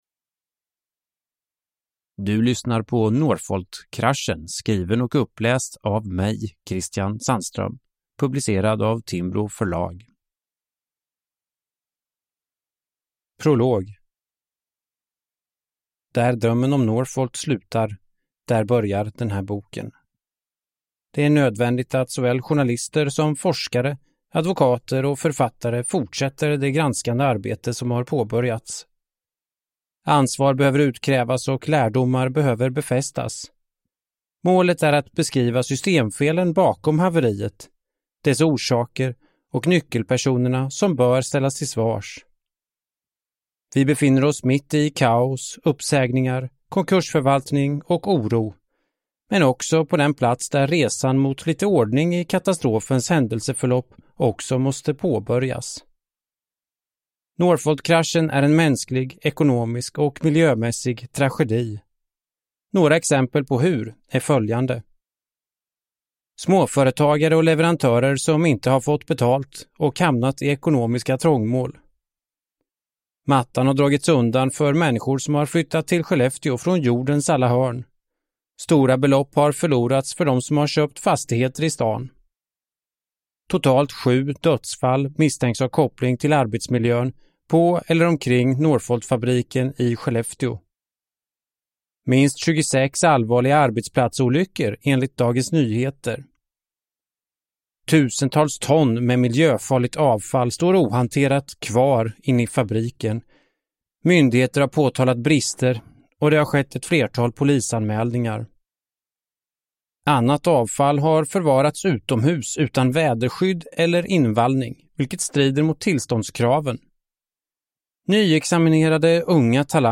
Northvoltkraschen – Ljudbok